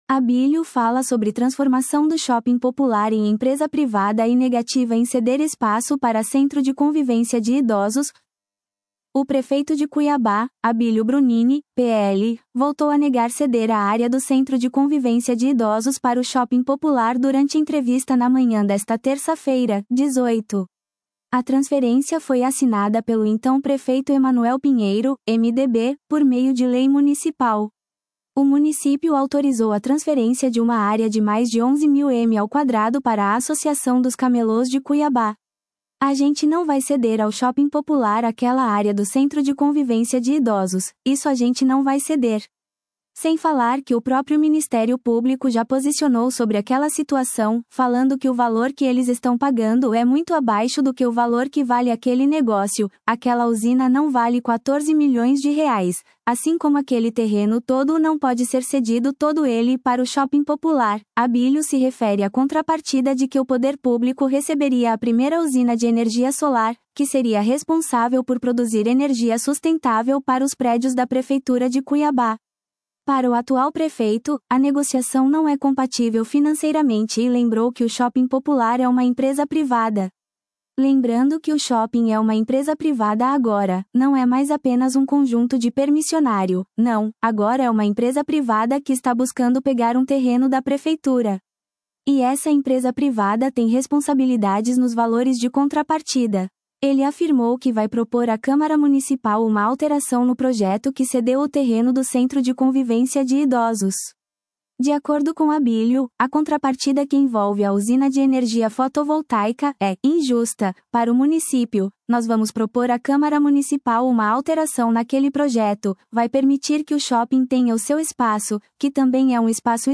O prefeito de Cuiabá, Abilio Brunini (PL), voltou a negar ceder a área do Centro de Convivência de Idosos para o Shopping Popular durante entrevista na manhã desta terça-feira (18).